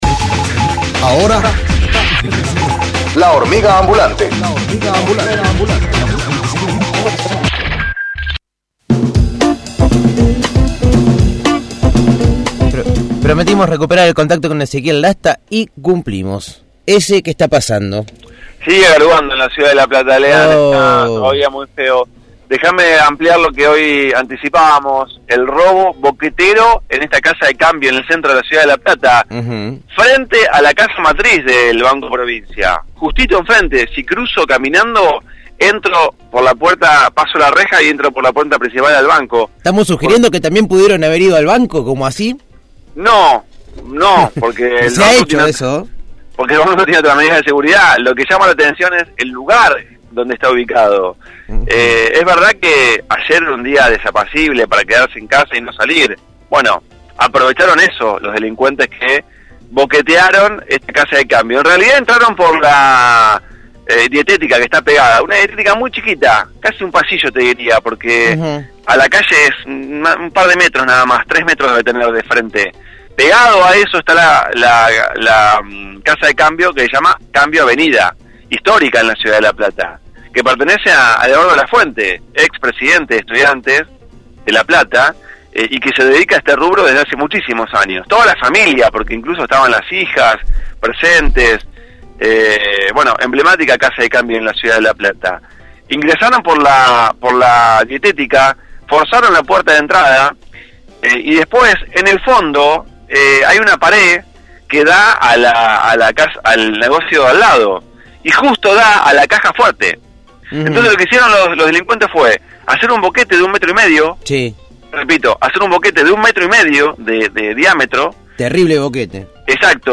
MÓVIL/ Boqueteros robaron la casa de cambio «Avenida» – Radio Universidad